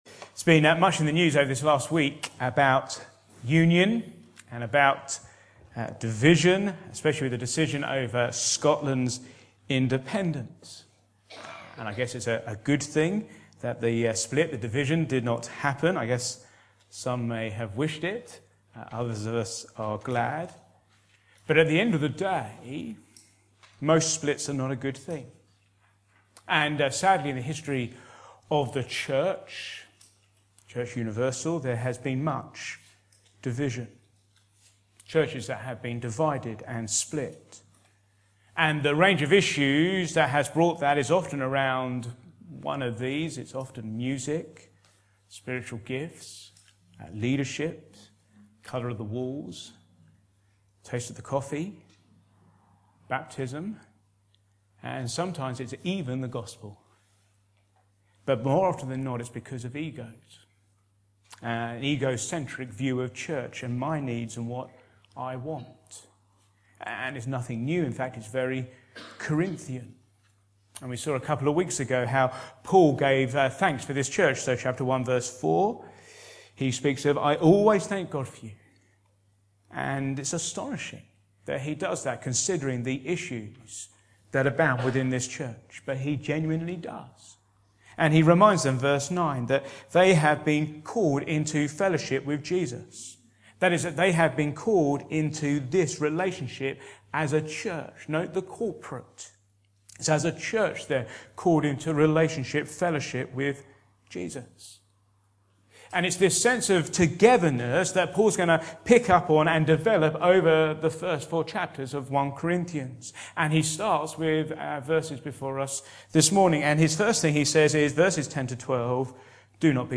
Back to Sermons Do not be divided